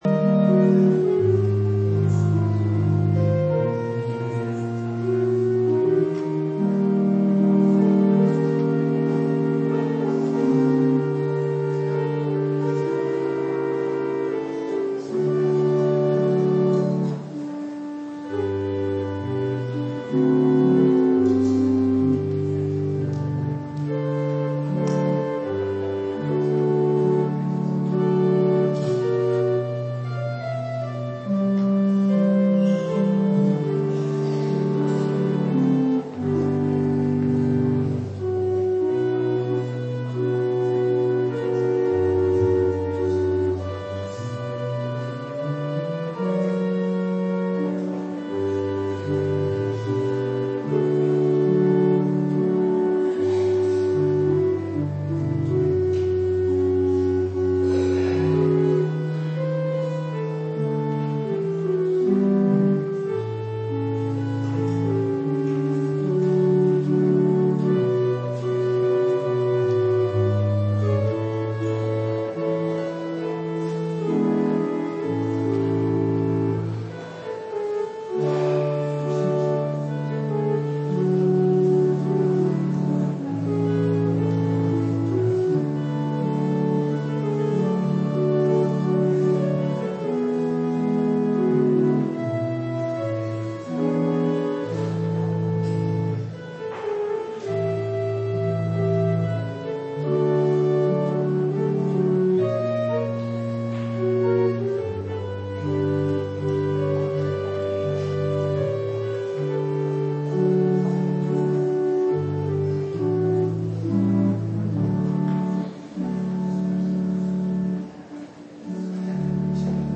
terugluisteren: Byzantijnse koorzang - Zondag der Voleinding
Er klonk Byzantijnse koorzang op de laatste zondag van het Kerkelijk jaar.
Het parochiekoor zong in de eucharistieviering meerstemmige koorzang en acclamaties uit de russisch-byzantijnse traditie.